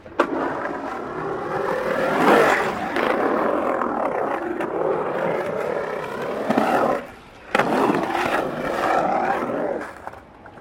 Звуки скейтборда
Шум скейта на роллердроме